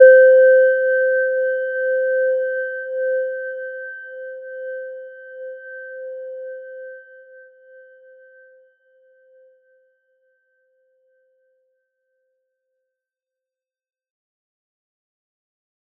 Gentle-Metallic-1-C5-mf.wav